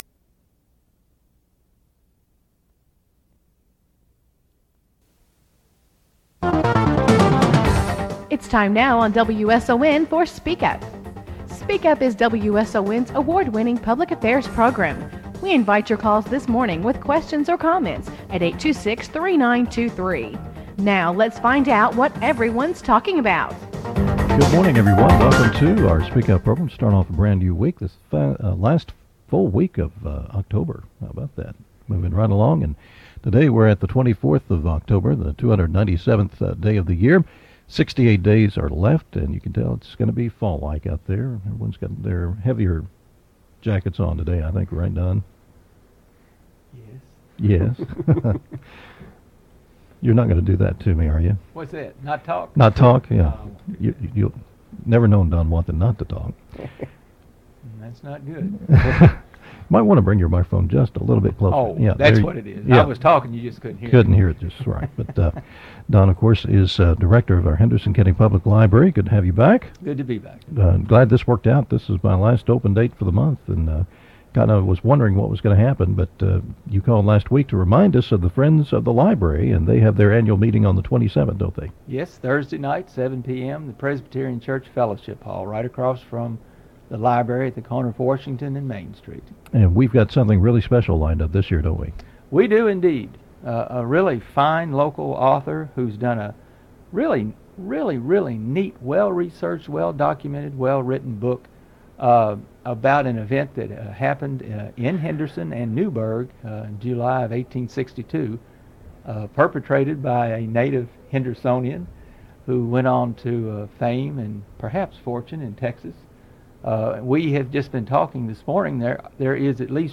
The first 7 seconds of the file are silent, then the interview starts. The interview is about 22 minutes long.